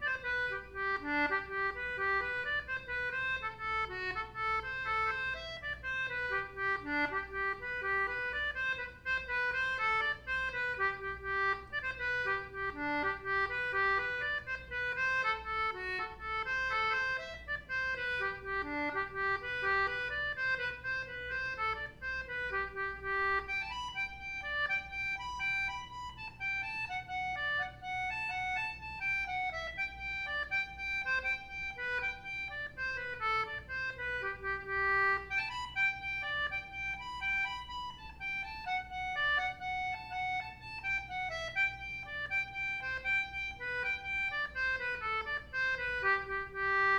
Celtic Button Box Playlist Samples
JIGS
English concertina